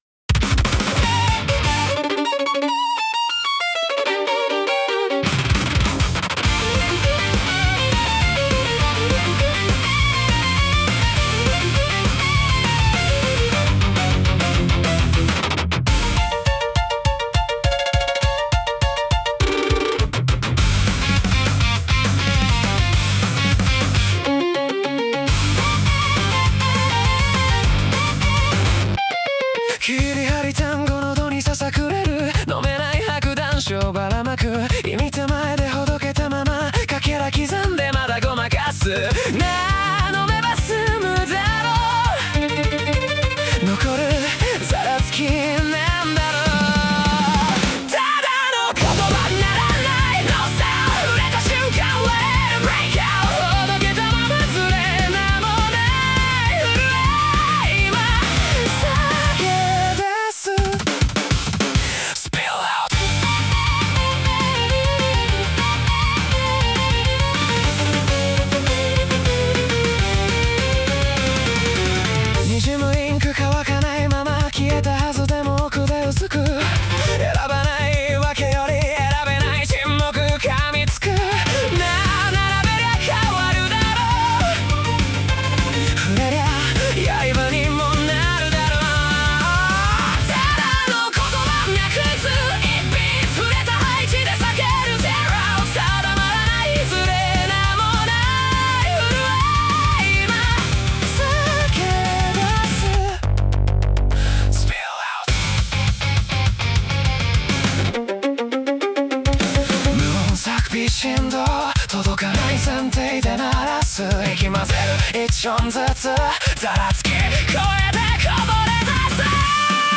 男性ボーカル
イメージ：ポストロック,マスロック,ダークオルタナティブ,男性ボーカル,ヴァイオリン,re-born,名もない震え